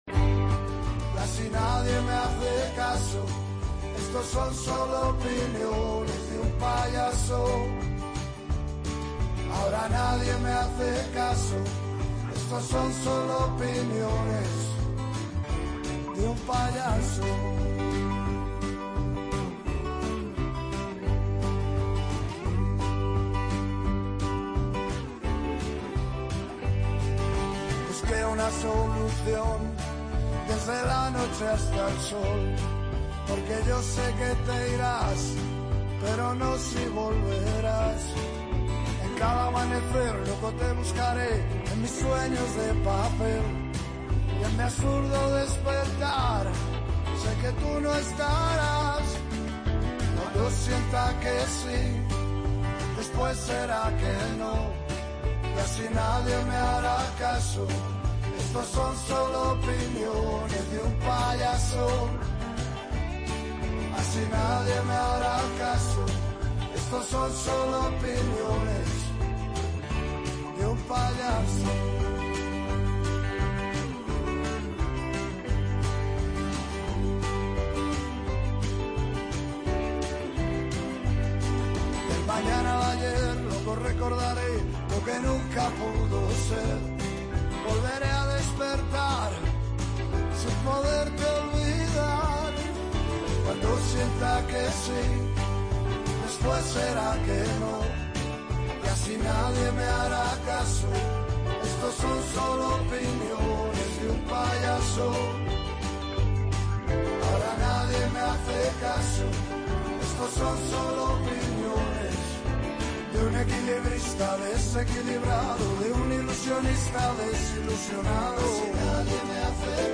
Escuche la entrevista con Nacho Hernando.